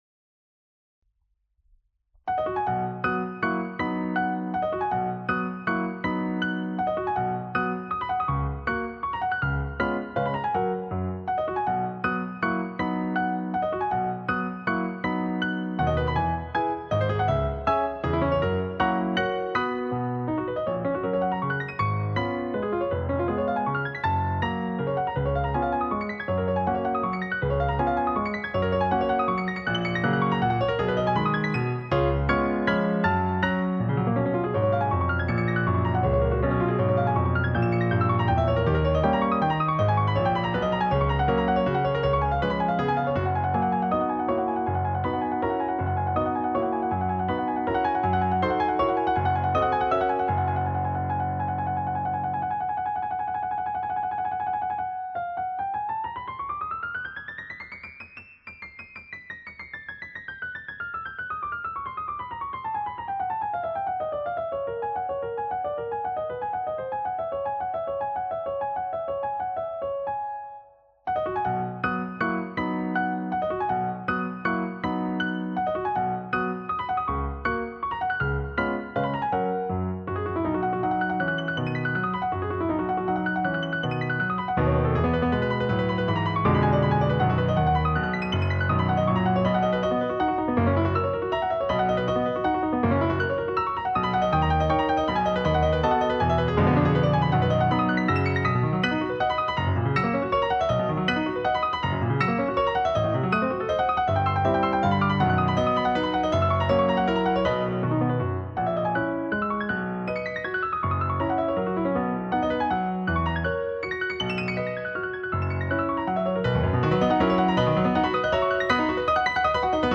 Piece: Allegro Vivace e Giocoso, Composer: Carl Czerny, Suite: Das moderne Klavierspiel Op.837 No.7